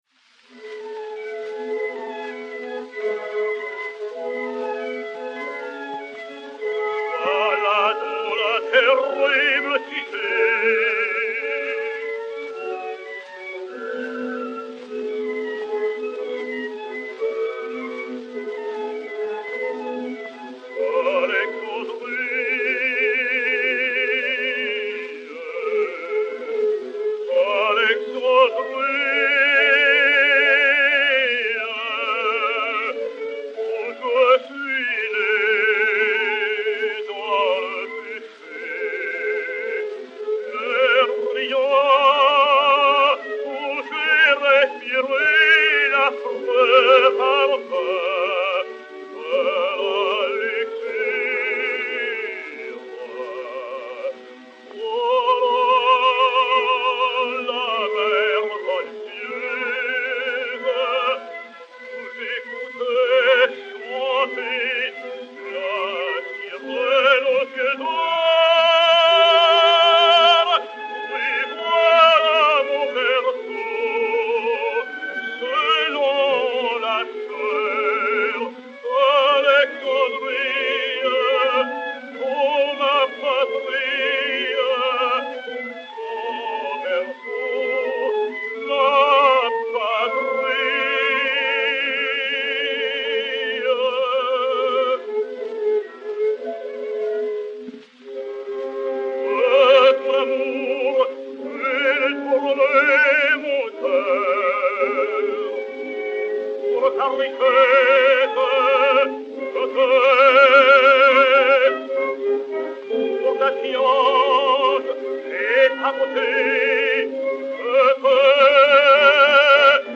baryton français
et Orchestre